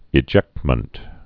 (ĭ-jĕktmənt)